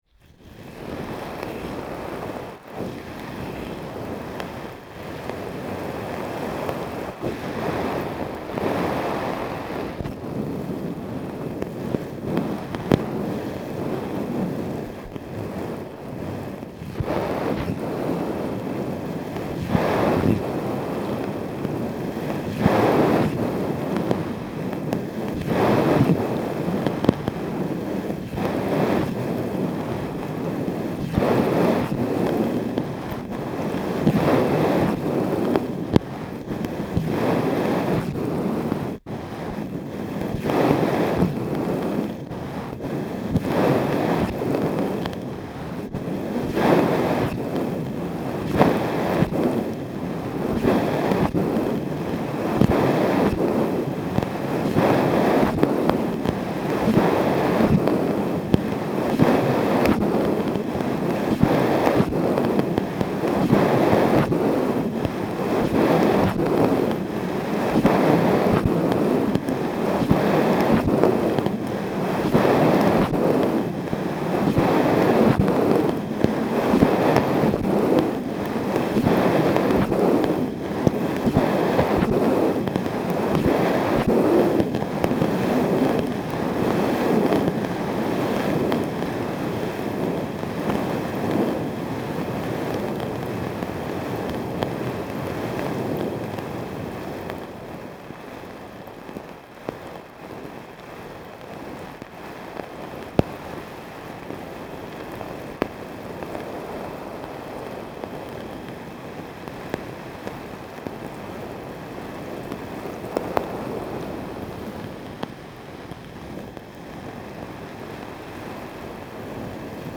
Contact-sounds
Uldkappe inderfor, MD21.mp3